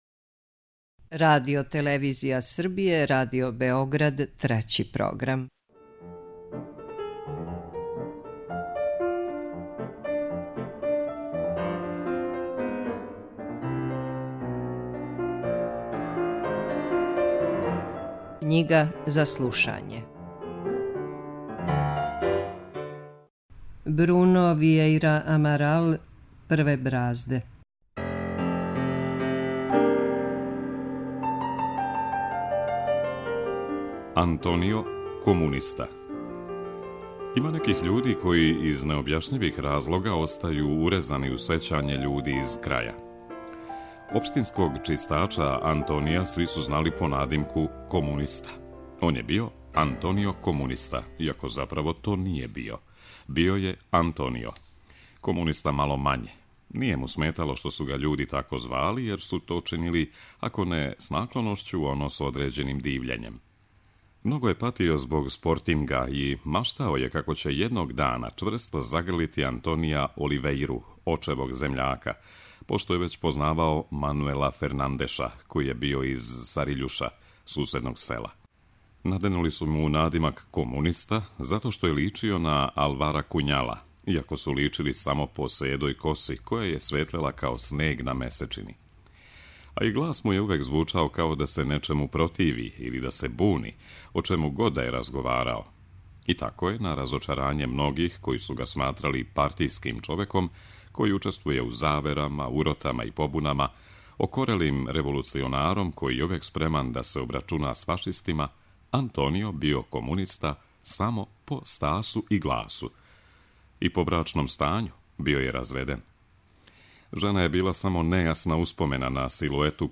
U emisiji KNjIGA ZA SLUŠANjE od 14. avgusta do 10. septembra na talasima Trećeg programa možete pratiti roman „Prve brazde”, čiji je autor portugalski pisac Bruno Viejra Amaral.